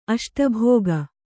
♪ aṣtabhōga